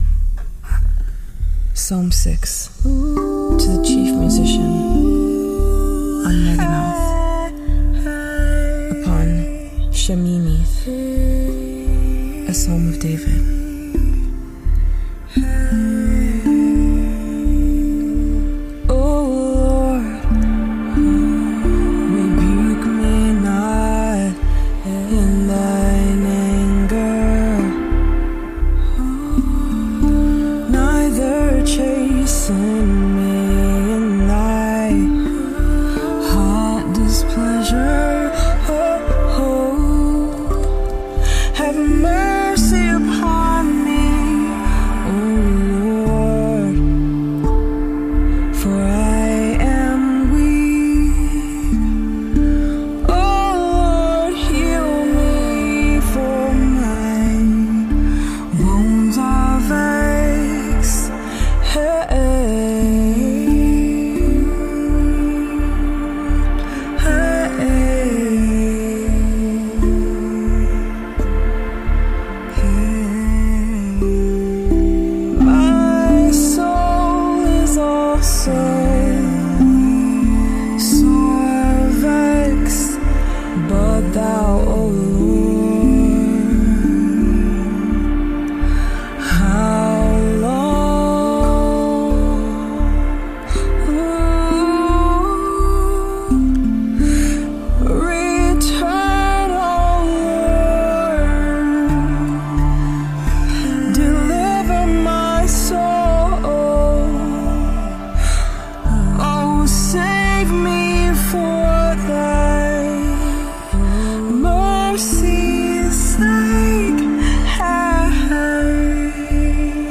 # March # music # Psalm # Psalm 6 # psalms # Rav # Rav vast # Rav vast drum # scripture # songs # tongue drum # word